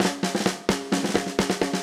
AM_MiliSnareA_130-01.wav